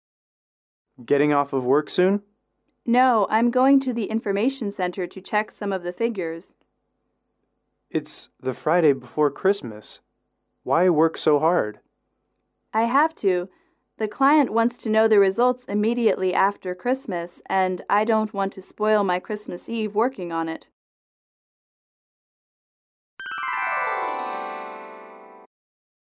英语口语情景短对话19-2：不得不加班(MP3)